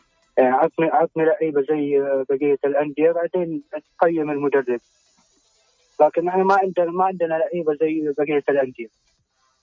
9_radio_jeddah.mp3